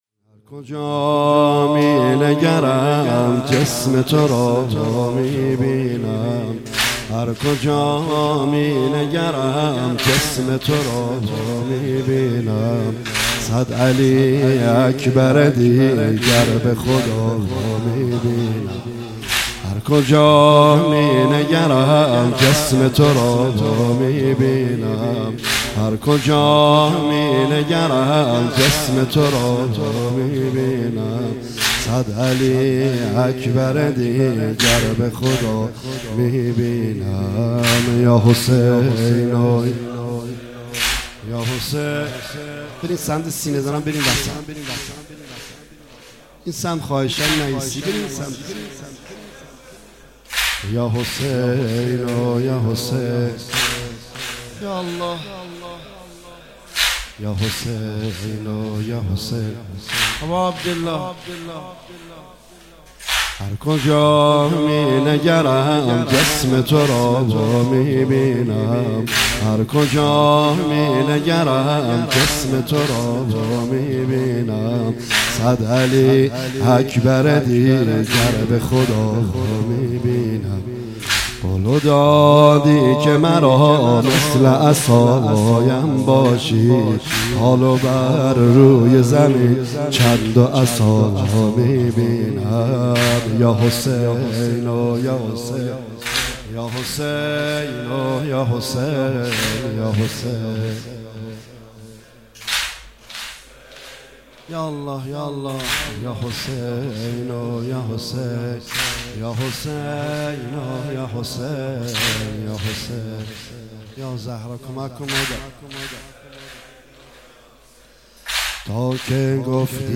متن نوحه